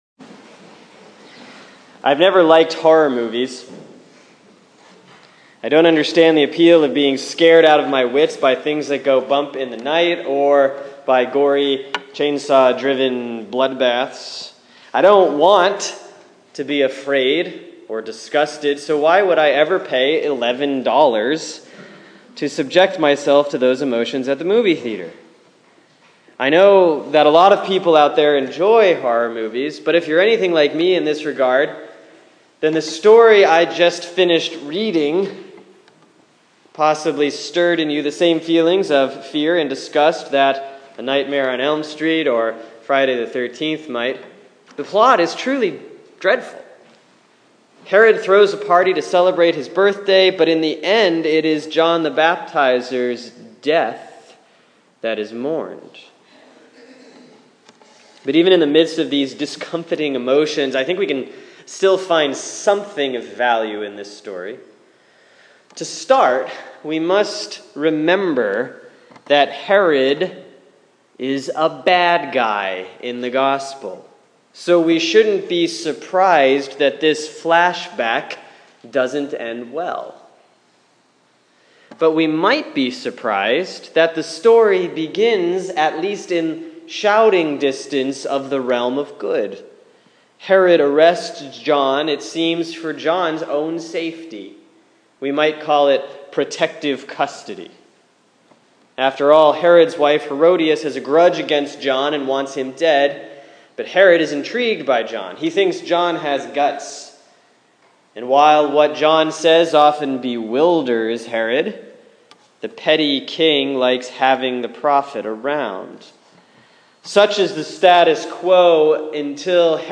Sermon for Sunday, July 12, 2015 || Proper 10B || Mark 6:14-29